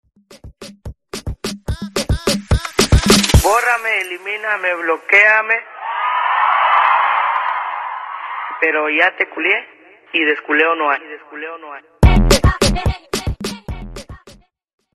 Category: Dembow